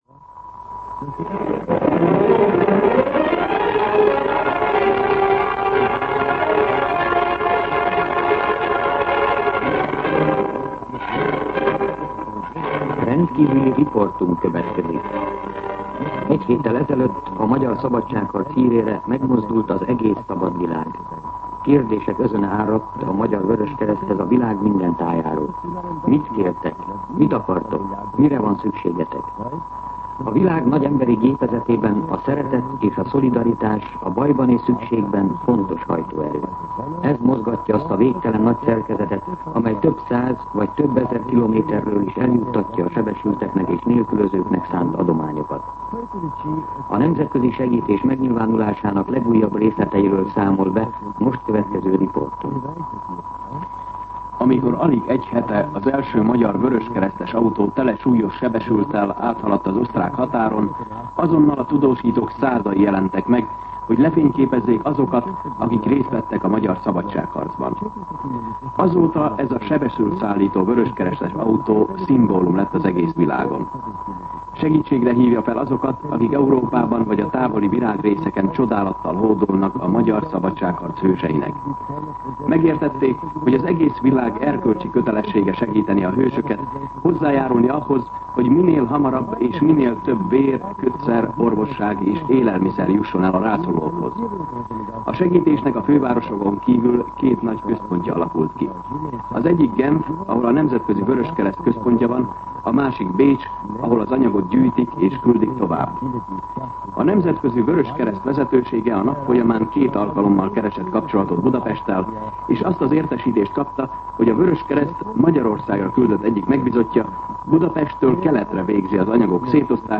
Rendkívüli riportunk következik.